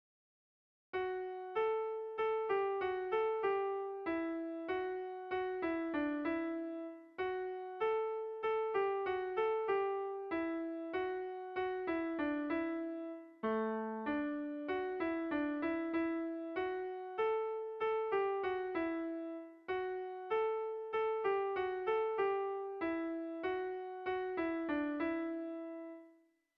Melodías de bertsos - Ver ficha   Más información sobre esta sección
Tragikoa
Zortziko txikia (hg) / Lau puntuko txikia (ip)
AABA